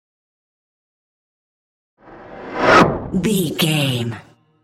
Whoosh fast bright
Sound Effects
Fast
futuristic
intense